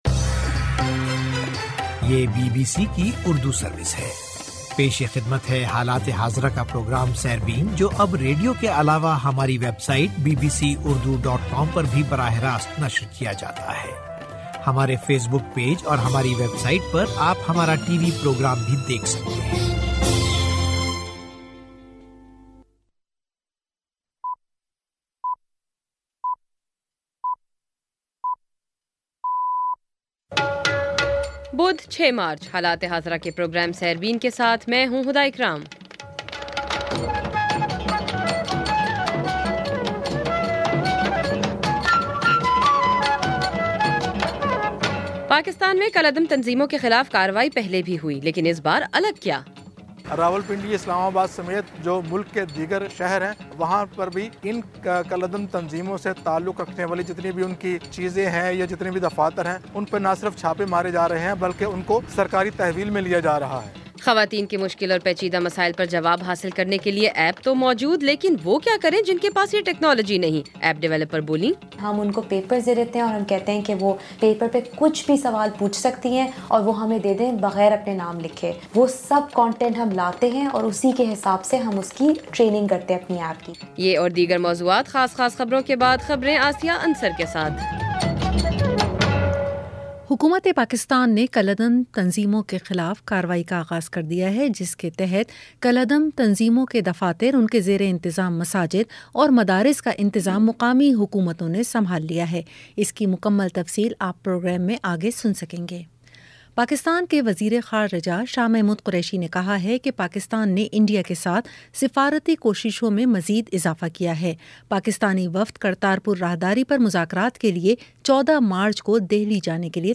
بدھ 06 مارچ کا سیربین ریڈیو پروگرام